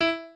pianoadrib1_42.ogg